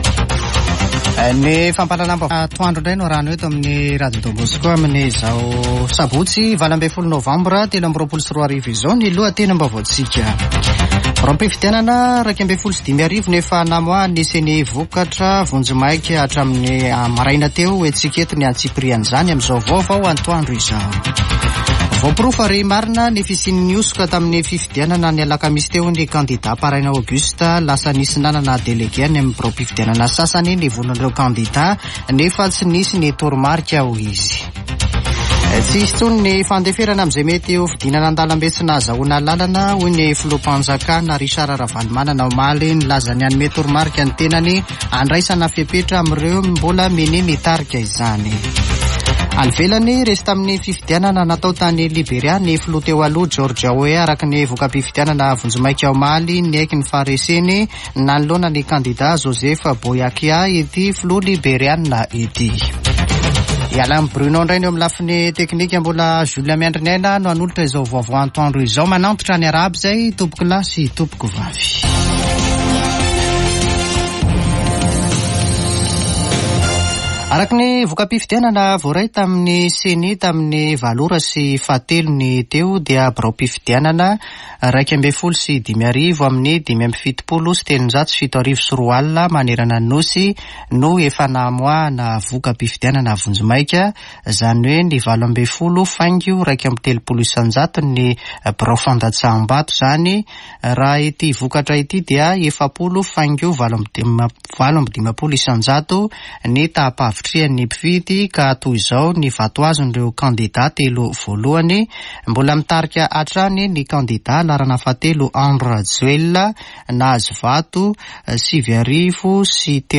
[Vaovao antoandro] Sabotsy 18 nôvambra 2023